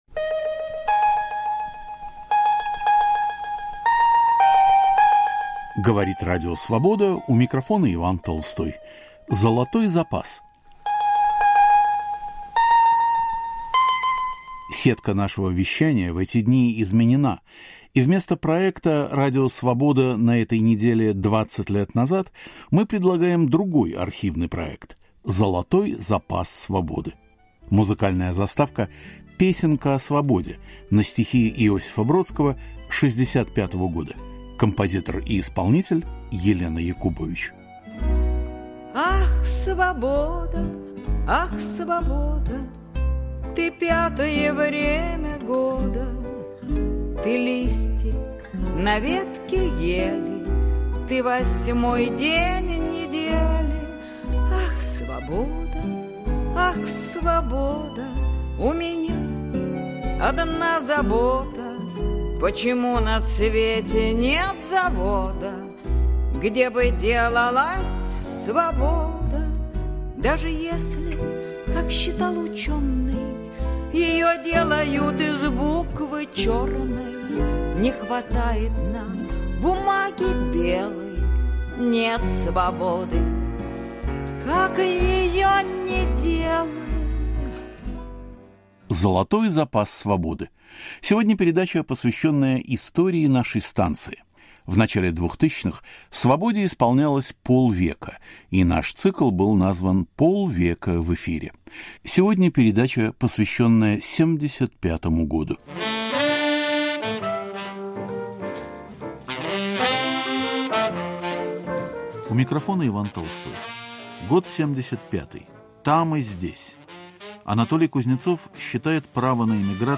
К 50-летию Радио Свобода. Из архивов: драма отказников, о продаже библиотеки Сергея Дягилева, Михаил Шемякин приезжает в Нью-Йорк. У микрофона поэт Иван Елагин.